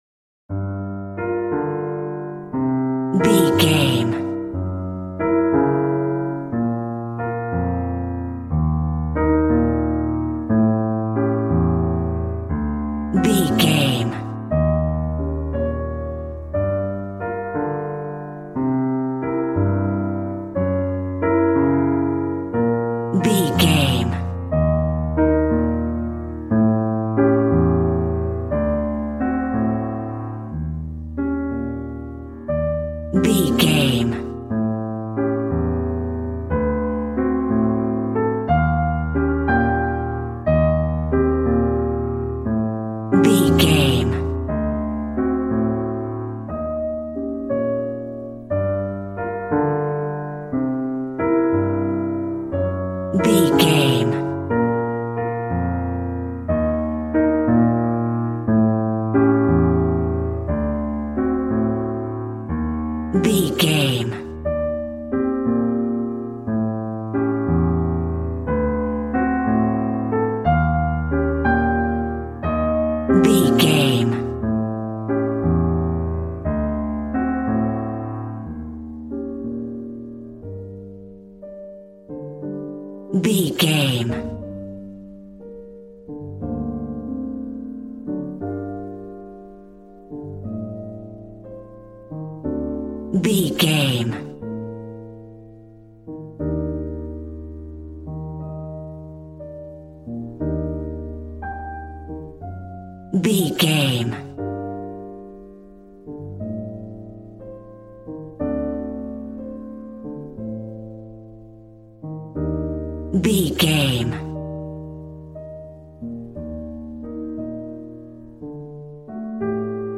Smooth jazz piano mixed with jazz bass and cool jazz drums.,
Aeolian/Minor
piano
drums